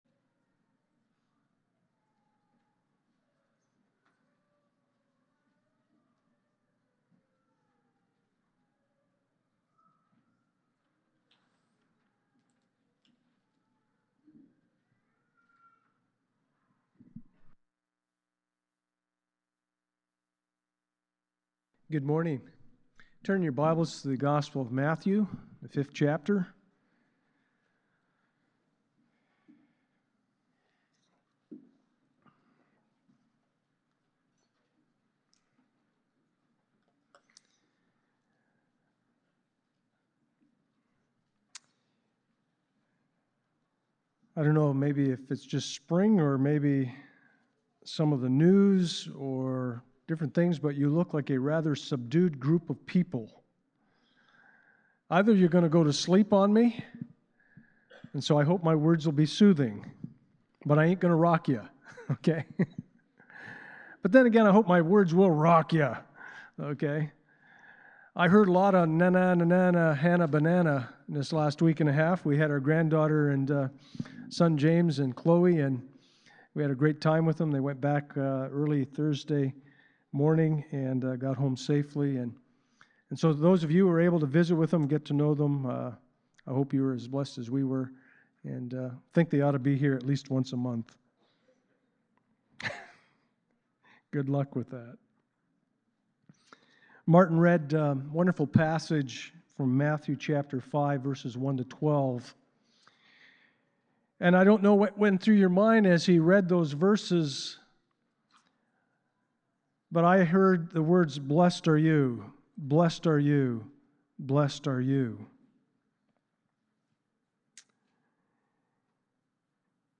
Micah 6:8-Psalm 6:8 Service Type: Sunday Morning Justice Justice